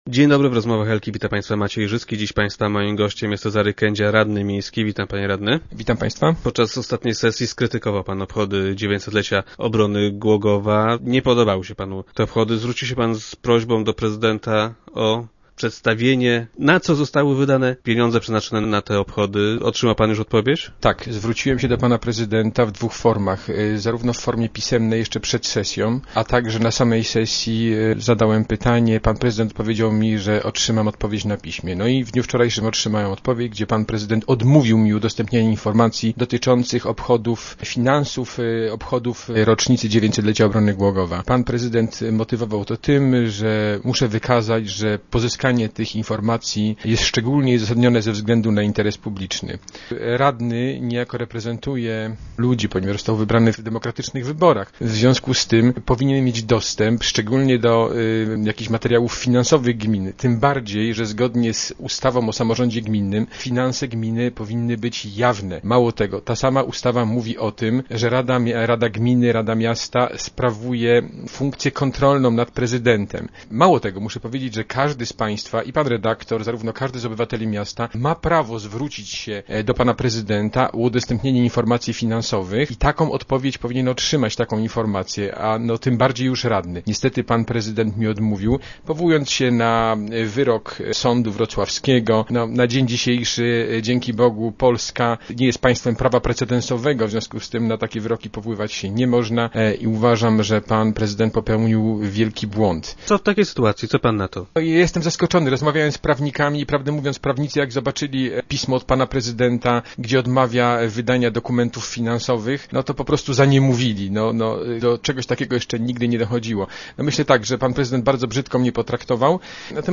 - Zwróciłem się do prezydenta o to na piśmie jeszcze przed sesją. Pytanie o wydatki zadałem także podczas obrad. Prezydent zapowiedział, że odpowiedź otrzymam na piśmie. W poniedziałek otrzymałem pismo, w którym prezydent odmówił mi udostępnienia informacji dotyczących finansów obchodów 900. rocznicy obrony Głogowa - powiedział podczas audycji radny Kędzia.